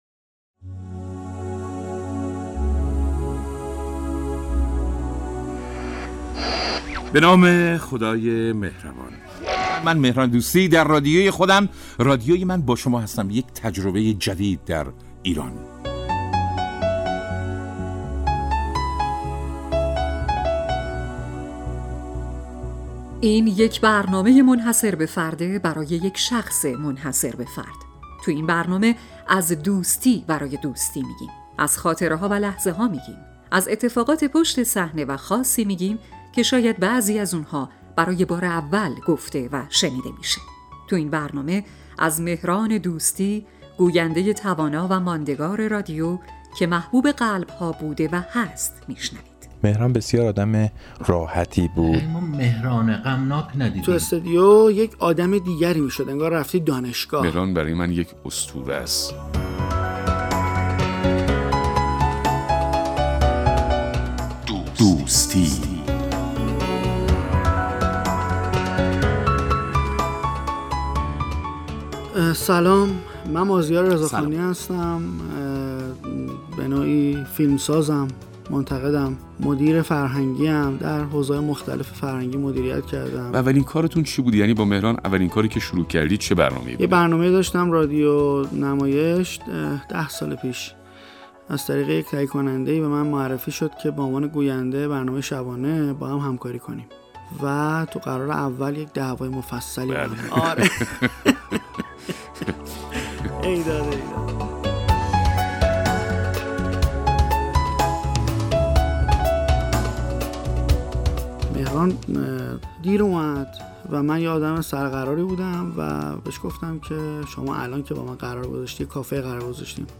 فیچر(مستند)رادیویی
اجزای این برنامه: مستندات آرشیوی ،مصاحبه،میزگرد و طراحی و اجرای پلاتو .